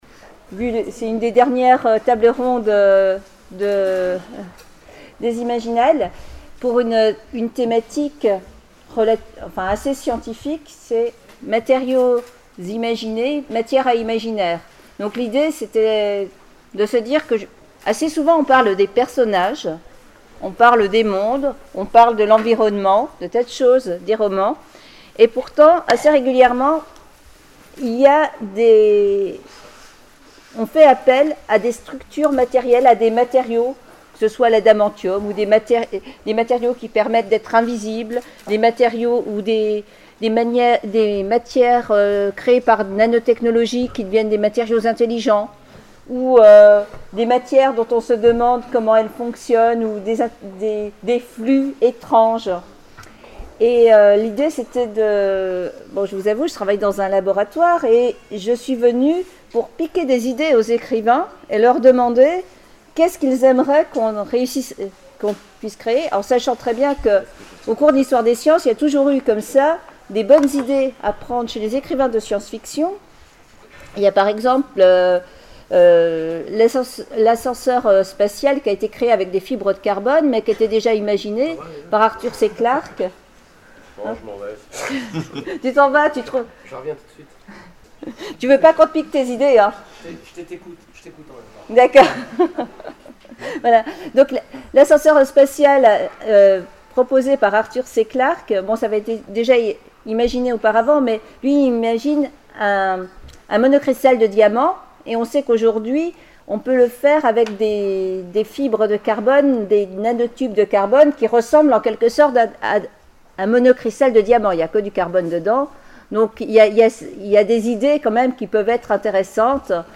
Imaginales 2012 : Conférence Science et Fiction, matériaux imaginés, matière à imaginaire